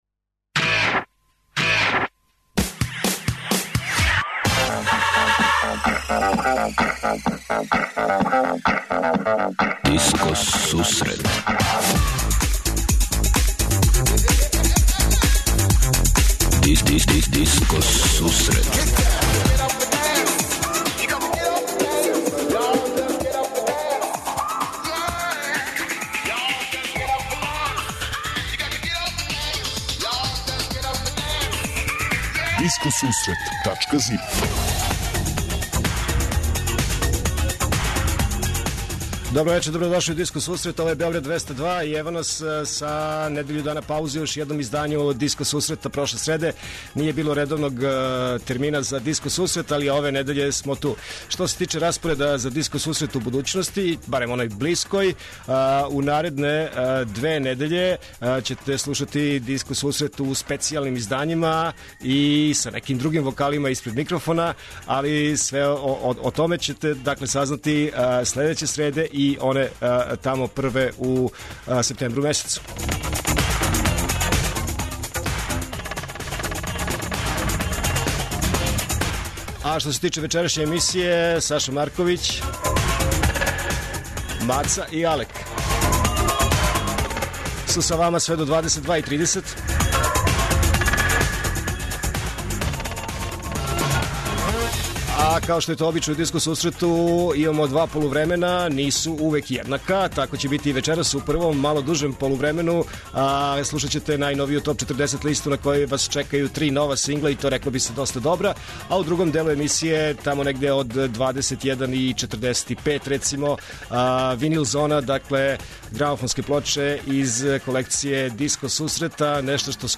Од 20:30 Диско Сусрет Топ 40 - Топ листа 40 највећих светских диско хитова.
Од 21:45 Винил Зона - Слушаоци, пријатељи и уредници Диско сусрета за вас пуштају музику са грамофонских плоча.